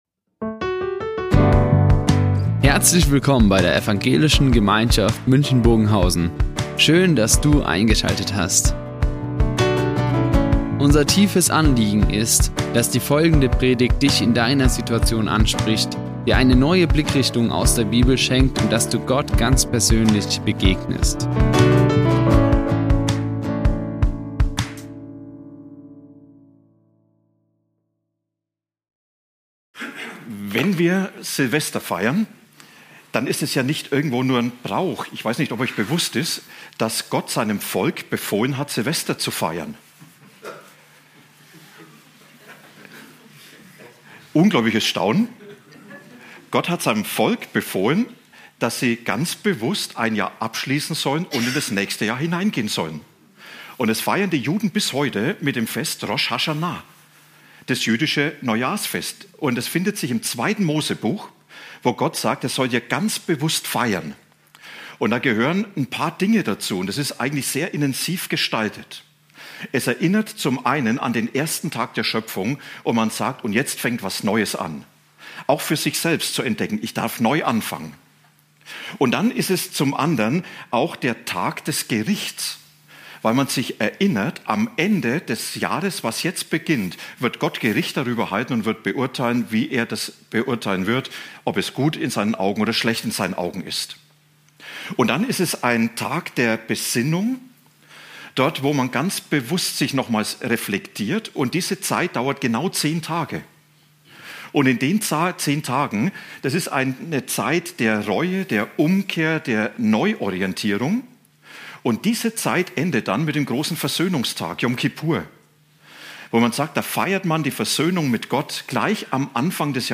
Alles und immer mit Jesus | Predigt Hebräer 13, 8-9 ~ Ev.